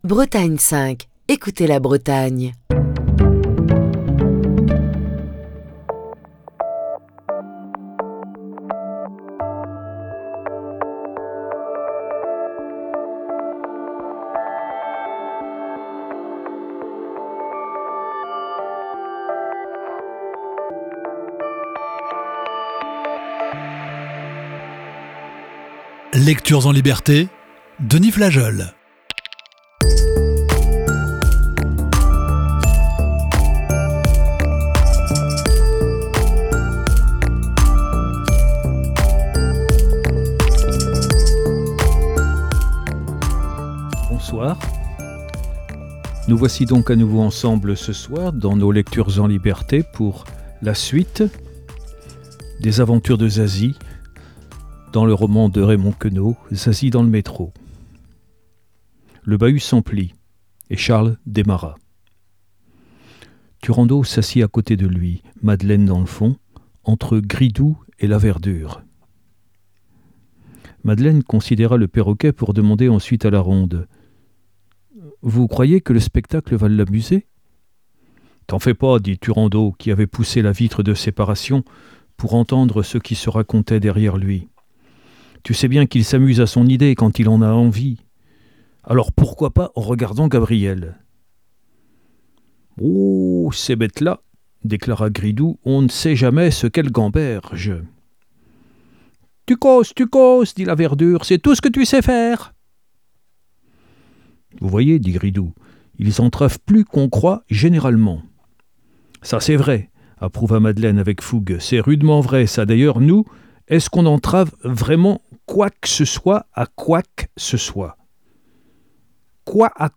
Lecture(s) en liberté